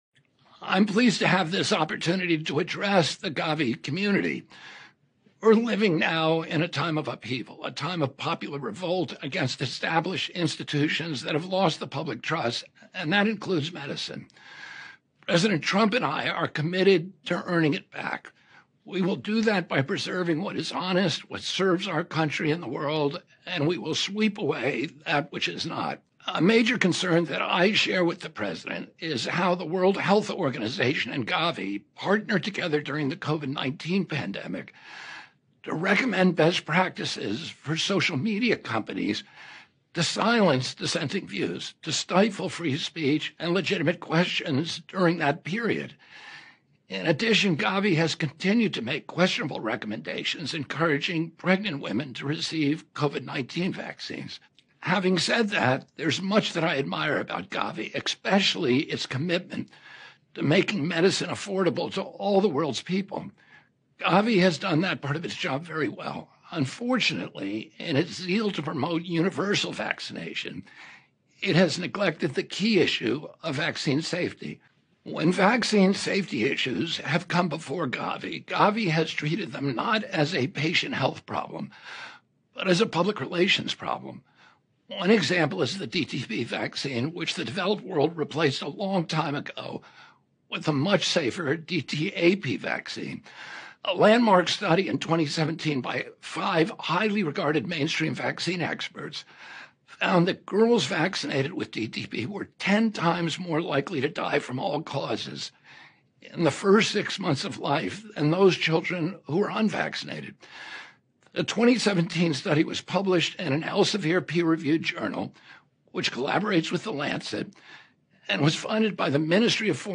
רוברט קנדי גוניור בהצהרה לתשקורת והציבור